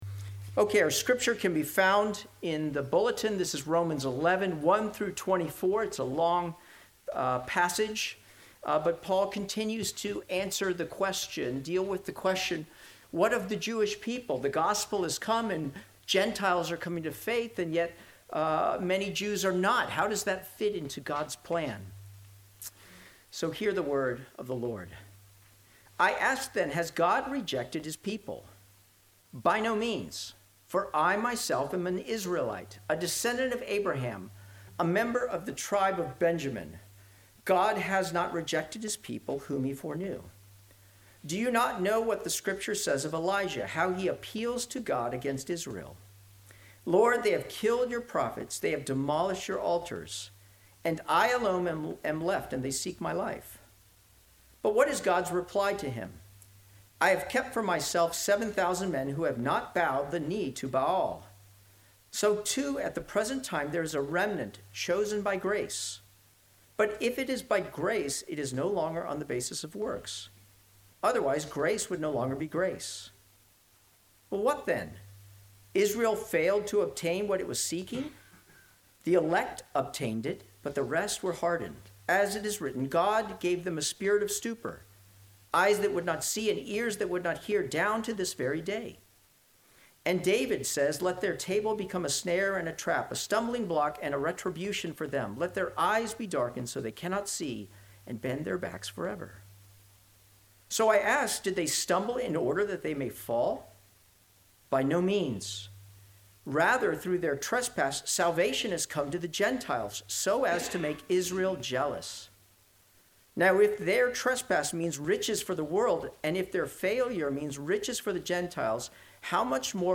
Sermons
Sermons from Redeemer Presbyterian Church in Virginia Beach, VA.